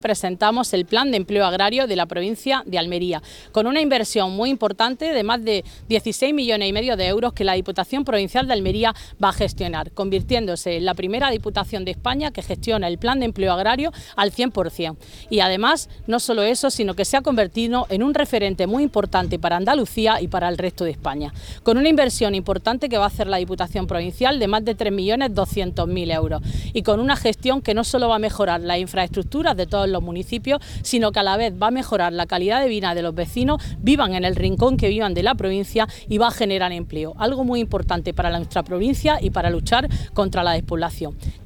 Este plan ha aumentado la inversión total y se sitúa en los 16,57 millones de euros y que se traduce en más de 250 actuaciones por toda la provincia. Así lo ha detallado la diputada del PFEA, Matilde Díaz, en la presentación del Plan 2024/25, quien ha estado acompañada de la delegada territorial de Justicia, Administración Local y Función Pública, Rebeca Gómez, y de la alcaldesa de Albox, María del Mar Alfonso.
16-12_plan_empleo_agrario_diputada.mp3